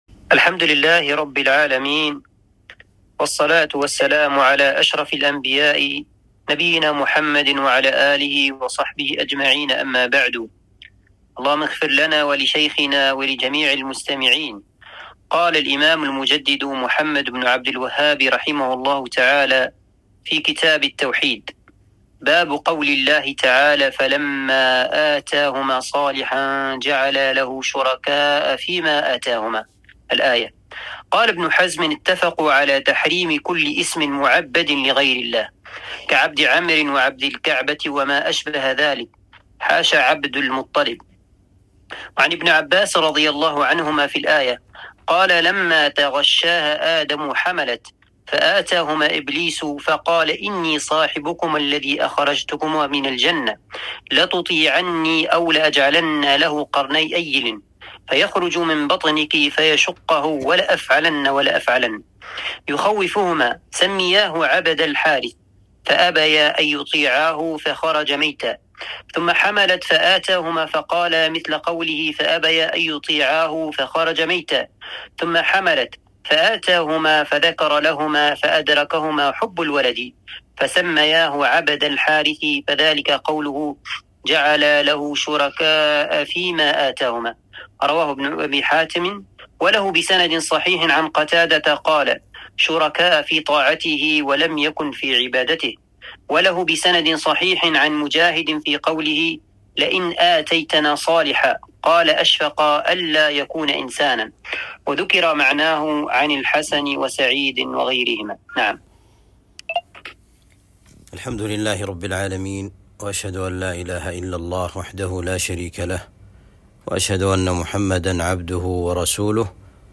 درس شرح كتاب التوحيد (47)